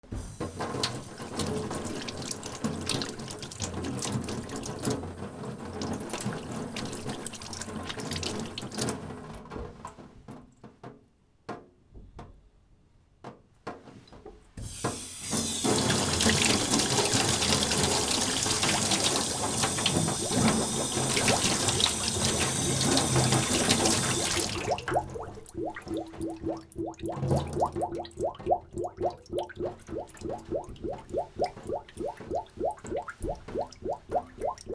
水道 シンク 洗う
/ M｜他分類 / L30 ｜水音-その他